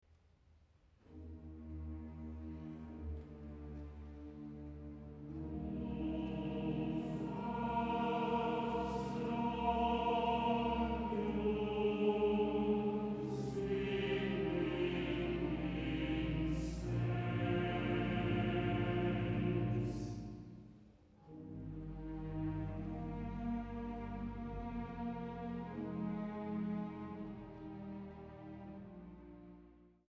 Sopran
Bariton
Allegro brillante (Scherzo)